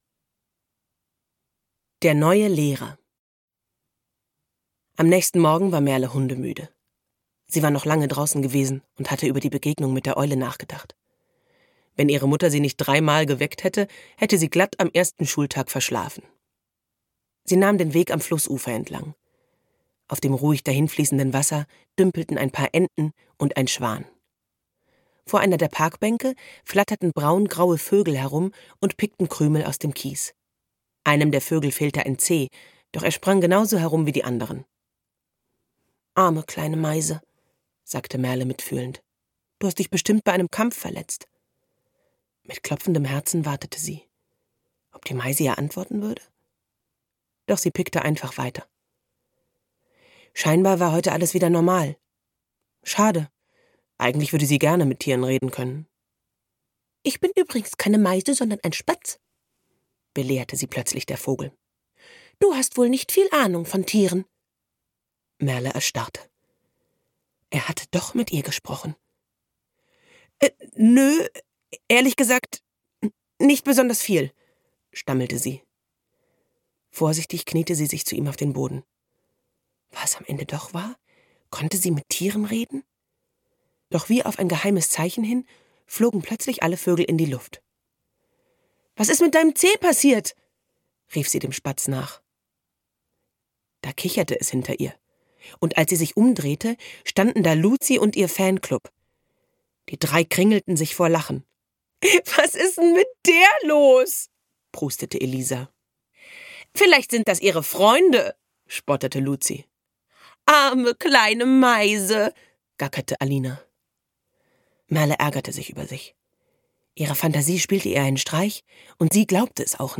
Die Tierwandler 1: Unser Lehrer ist ein Elch - Martina Baumbach - Hörbuch